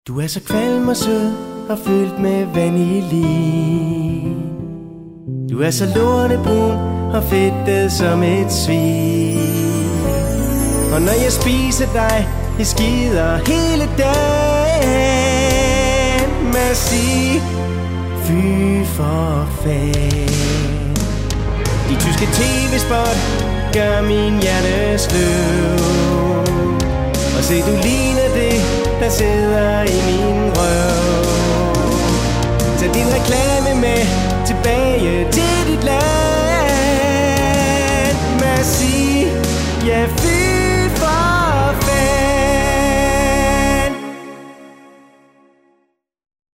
Det er her, du kan høre alle de gode, gamle indslag fra ANR's legendariske satireprogram.
For anden gang i Farlig Fredags historie blev redaktionen samlet til 3 timers "Farligt Nytår" nytårsaftensdag.